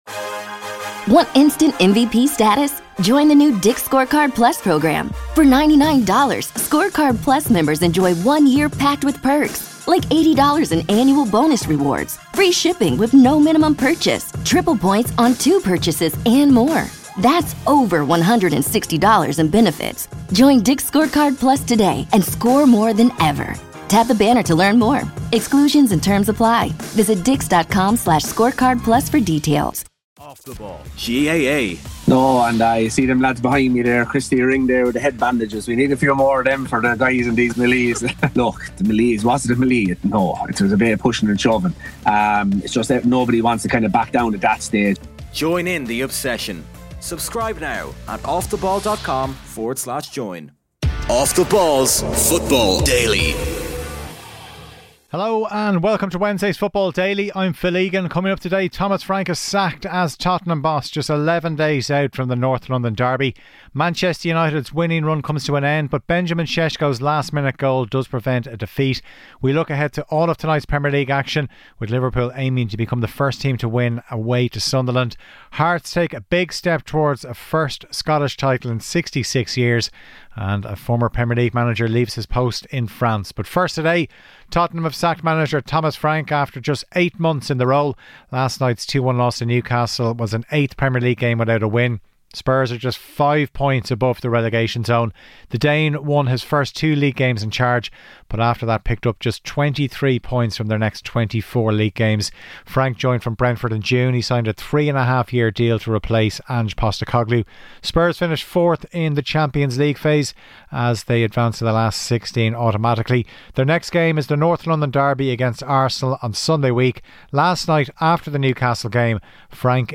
for a discussion on the managerial change, why blame should also be aimed at those running the football club and concerns around a chronic injury list.